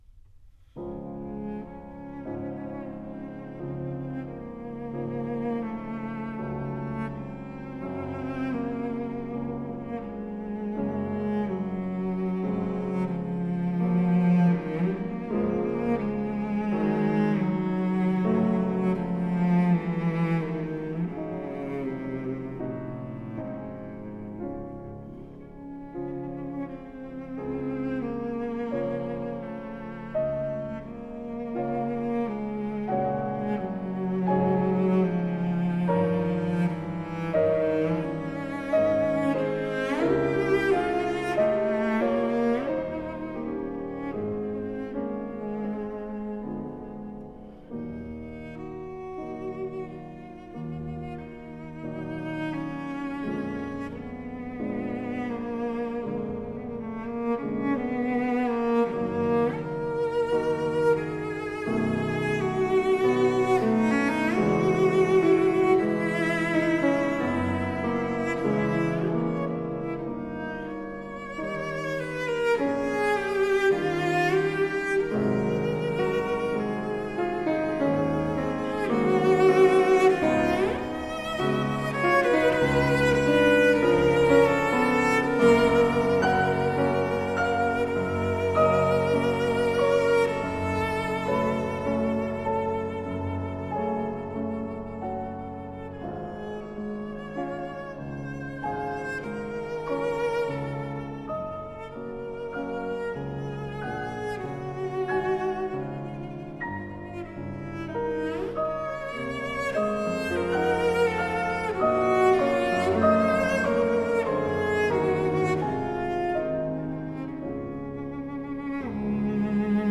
Piano Cello Duo
Works for cello and piano
The two play together as a seamless, homogenous unit.